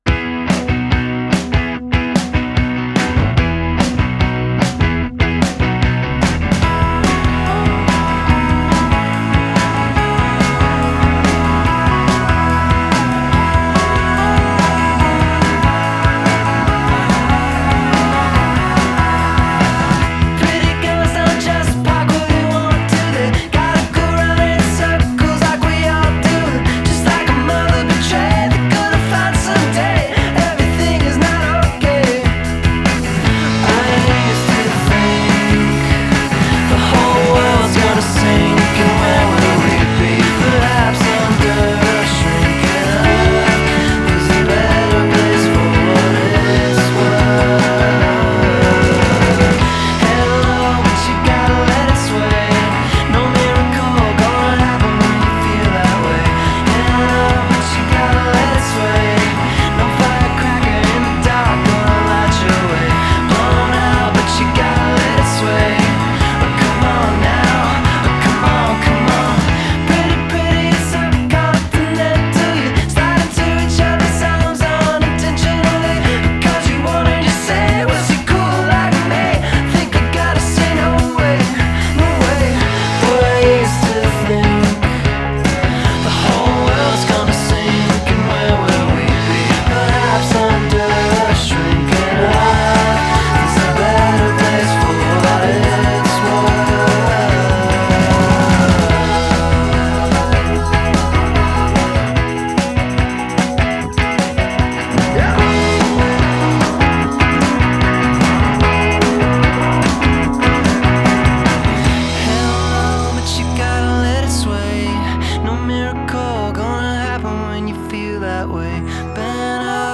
The Skinny : Bubbly, fun and actually awesome.
It’s powerful but at the same time, mellow.